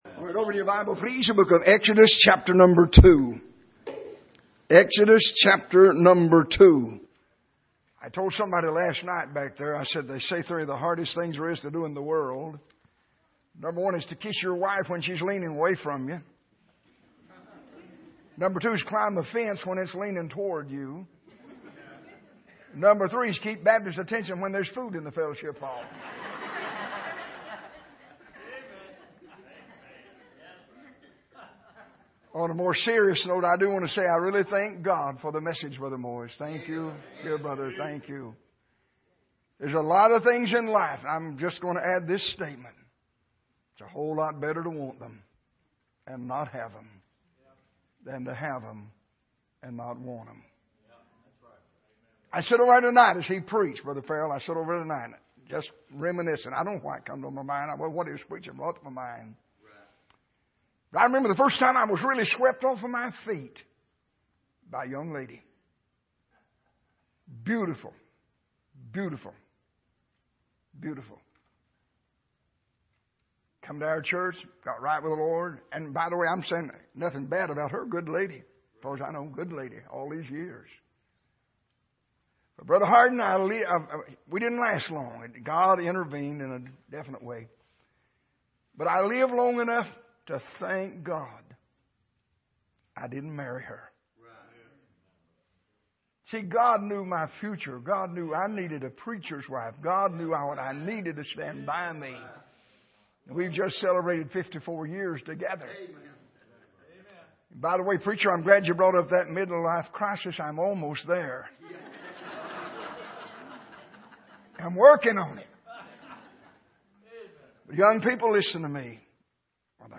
Here is an archive of messages preached at the Island Ford Baptist Church.
Service: Sunday Evening